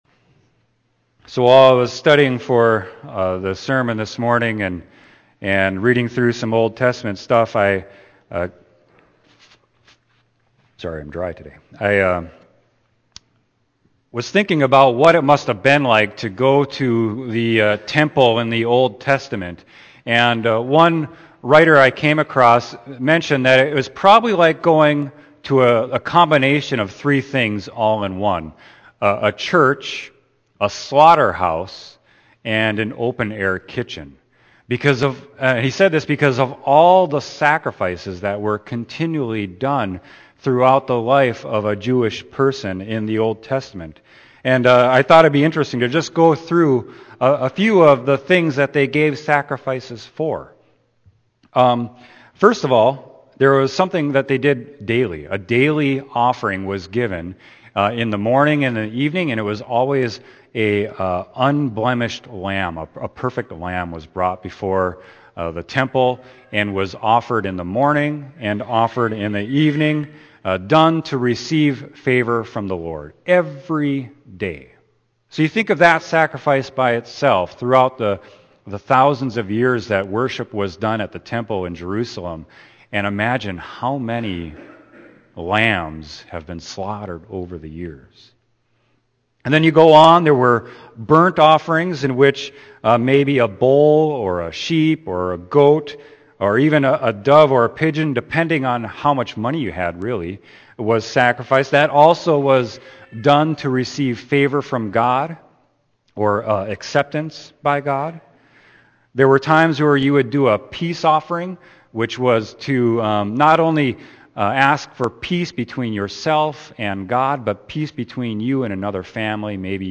Sermon: John 1.29-34